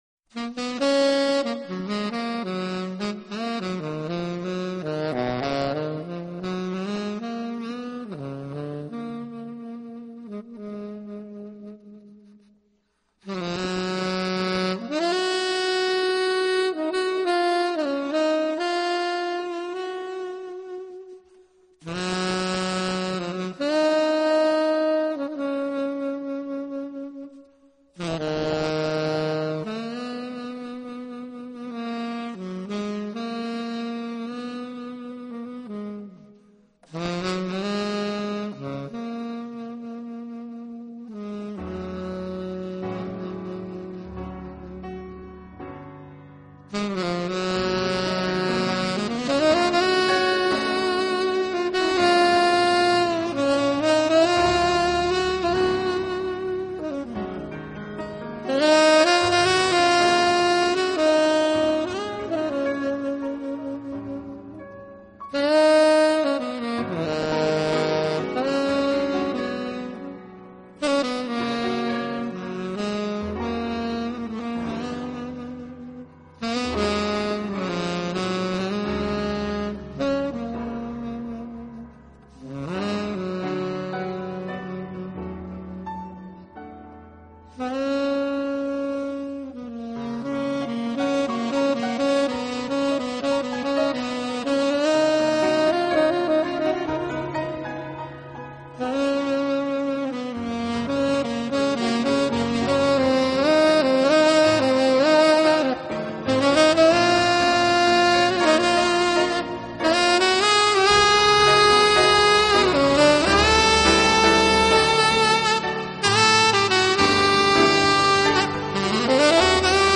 爵士萨克斯
音乐风格: Smooth Jazz